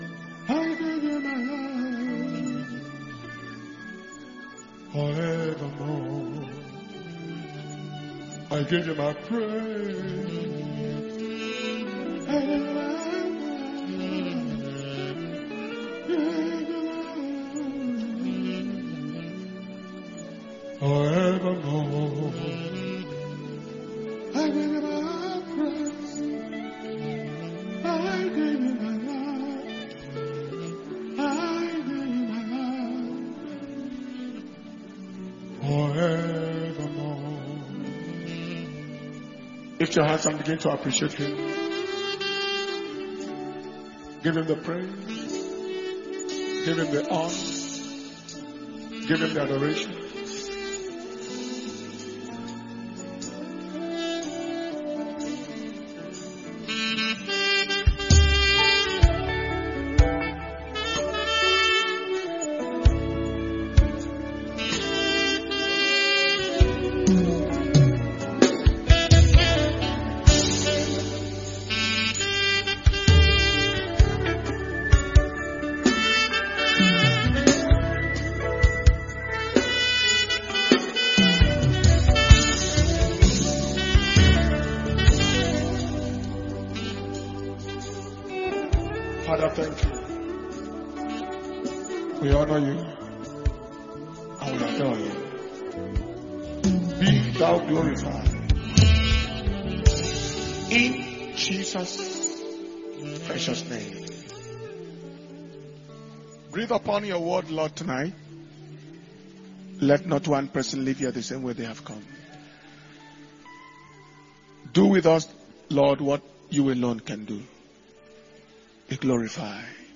June 2022 Preservation And Power Communion Service – Wednesday June 1st 2022